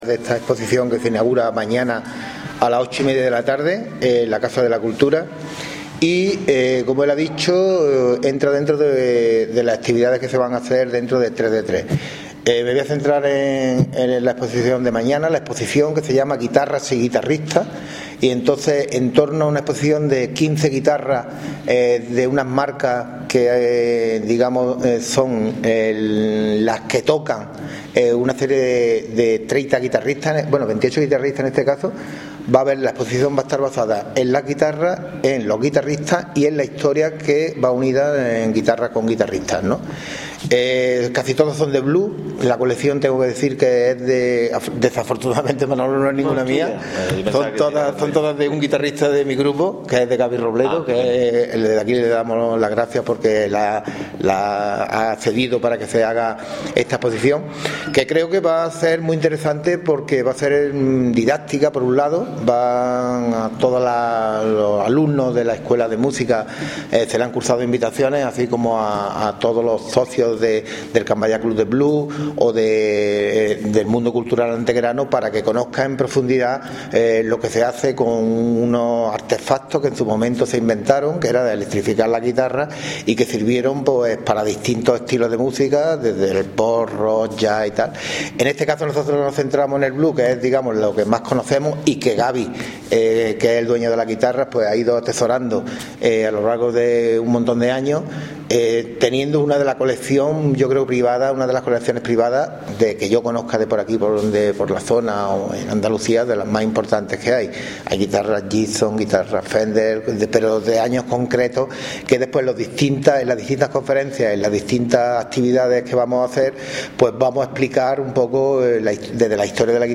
Cortes de voz
Audio: concejal de Juventud   2442.45 kb  Formato:  mp3